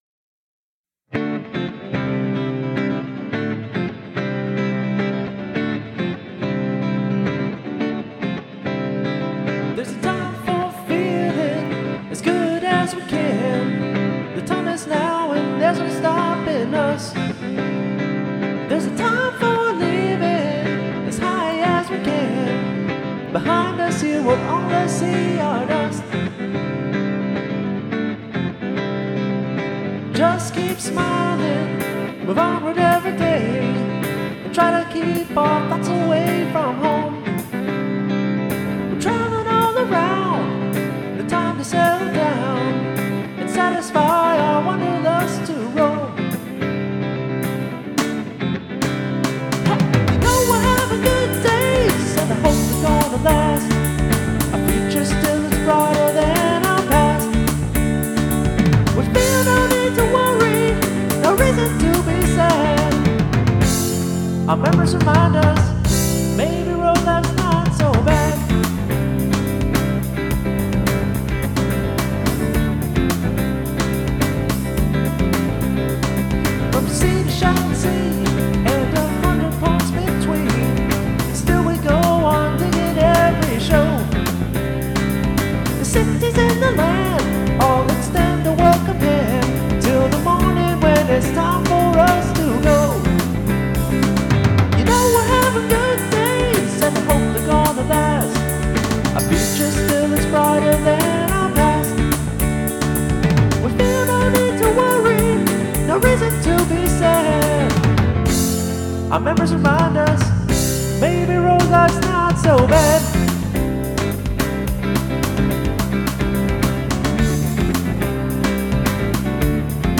We recorded this in about 3 takes...A simple classic!